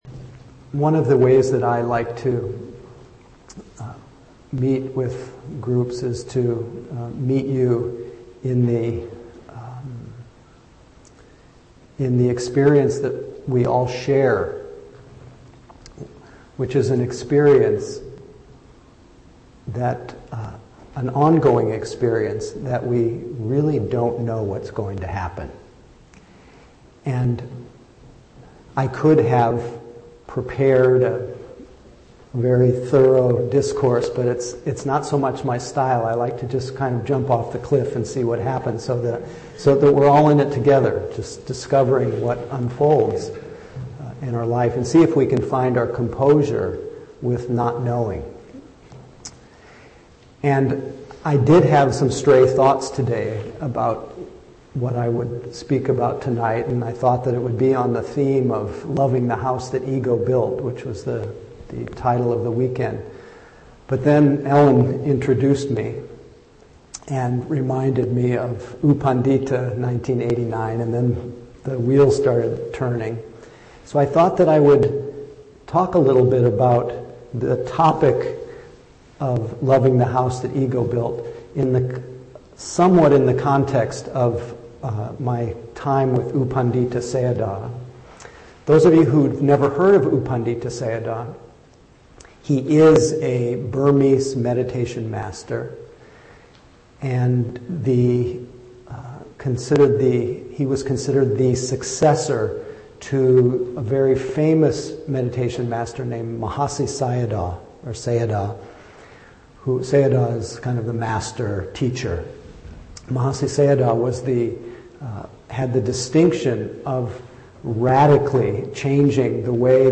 2010-12-03 Venue: Seattle Insight Meditation Center Series